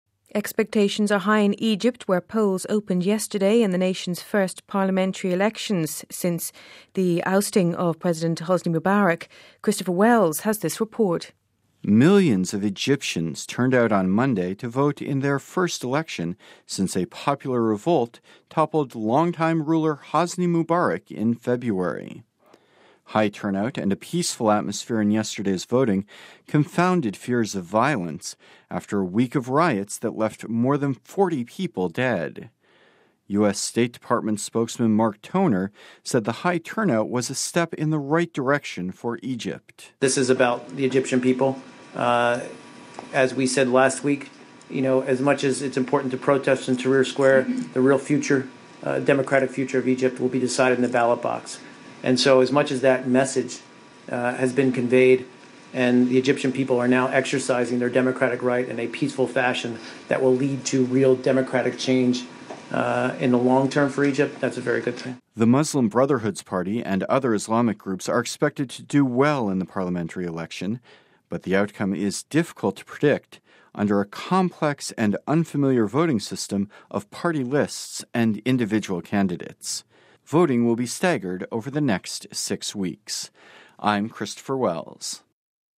US State Department spokesman Mark Toner said the high turnout was a step in the right direction for Egypt.